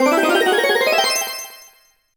level_up_01.wav